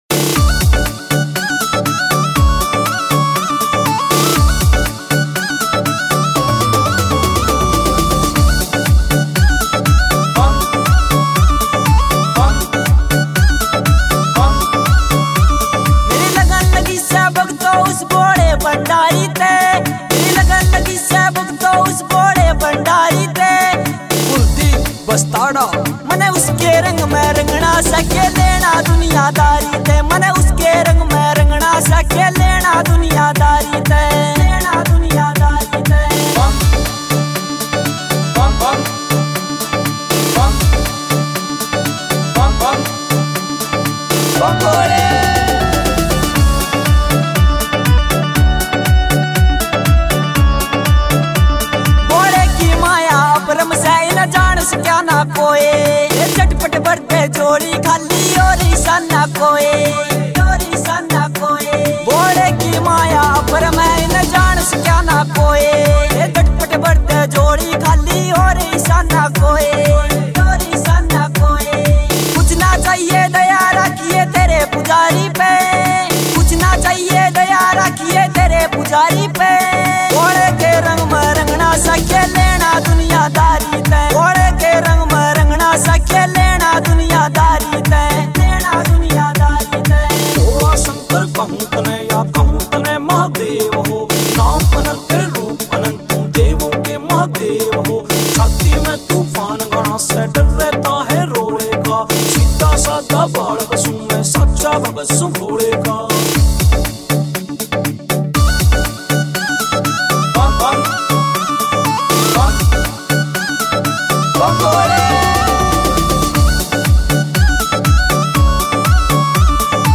Bhakti Songs
» Haryanvi Songs